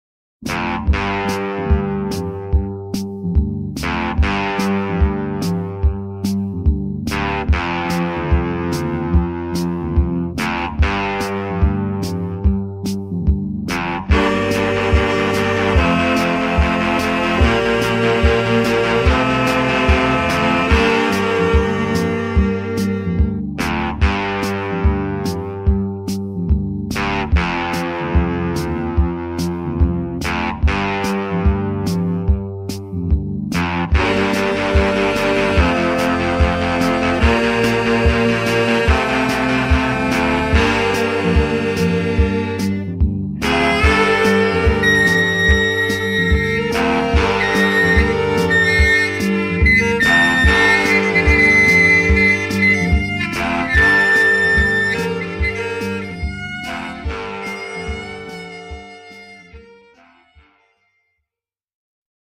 A mysterious tribute